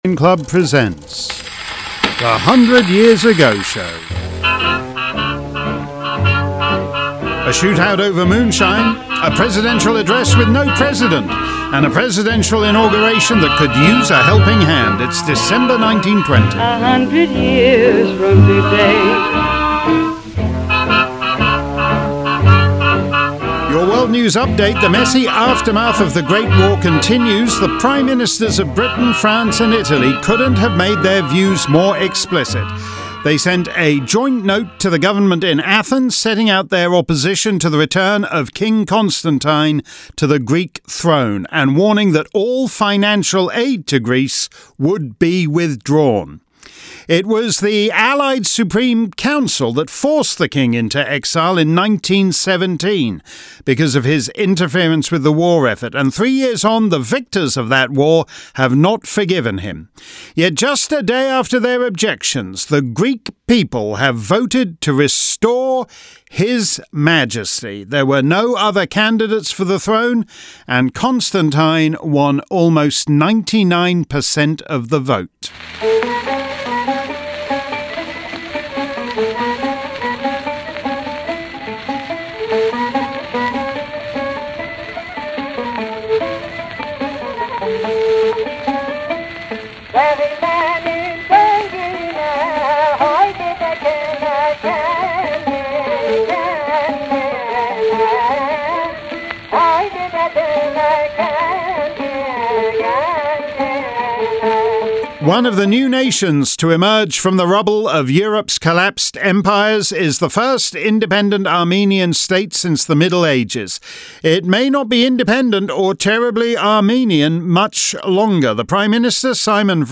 Plus all the other headlines from caterpillar tracks to Canton Bulldogs - and the sounds of the era from Mexico to the Sahara.